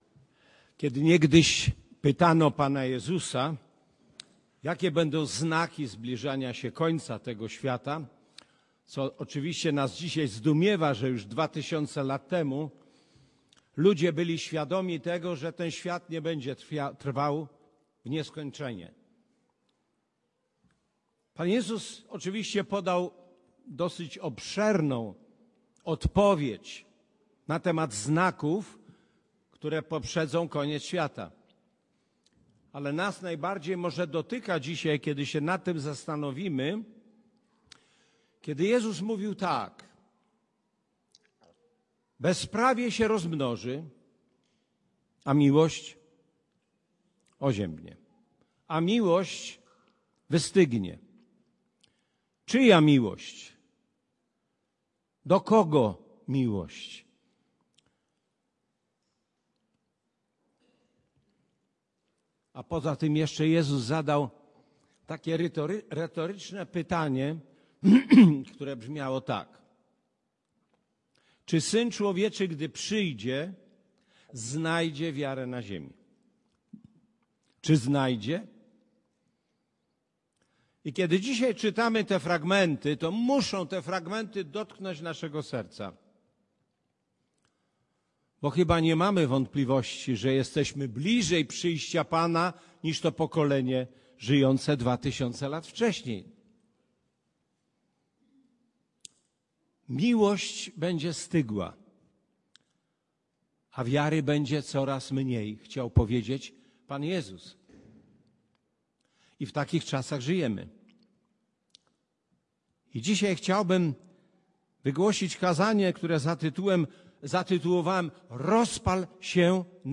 Passage: II List do Tymoteusza 1, 1-9 Kazanie